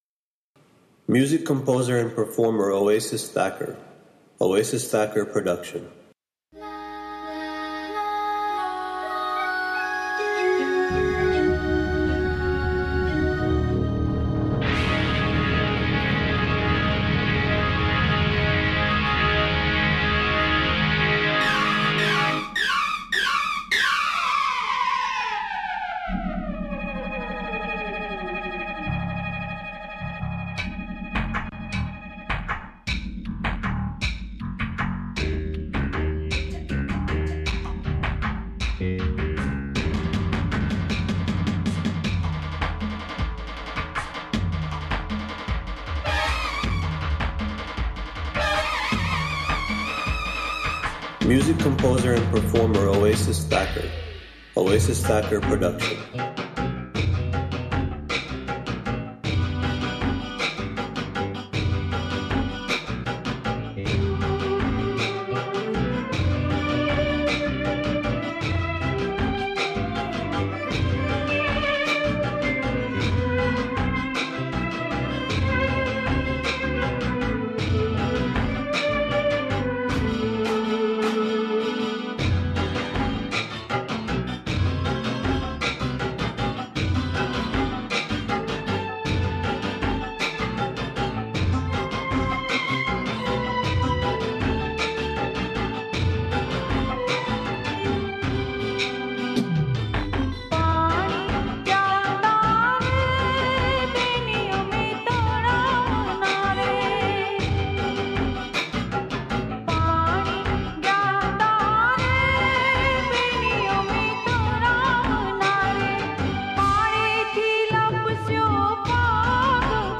[૩૦ મિનિટ નોનસ્ટોપ ડાંડિયા રાસની રમઝટ]
Chorous